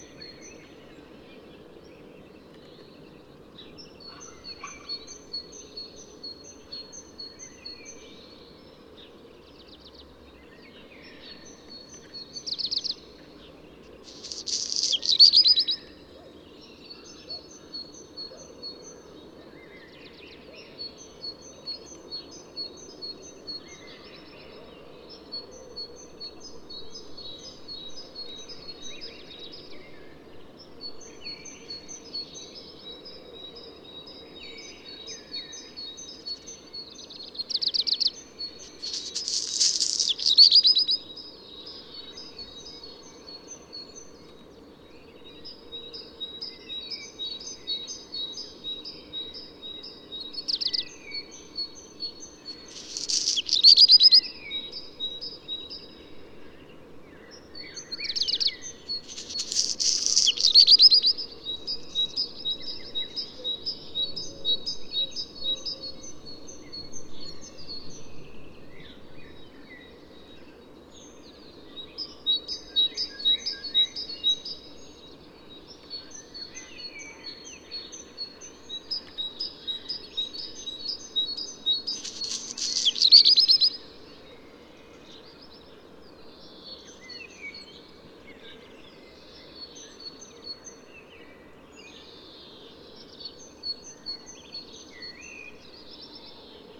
Sometimes a Black Redstart Phoenicurus ochruros sings at my windowsill.
100404, Black Redstart Phoenicurus ochruros, song, Leipzig, Germany
10_black-redstart.mp3